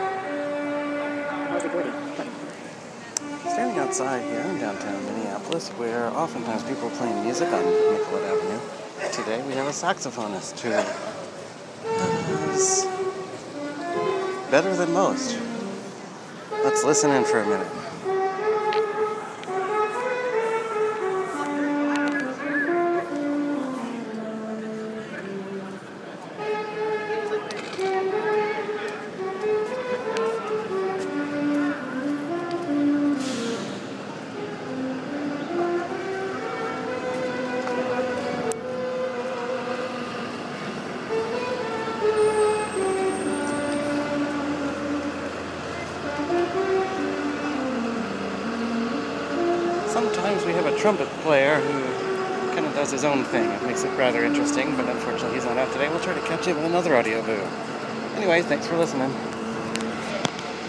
Random music from downtown Minneapolis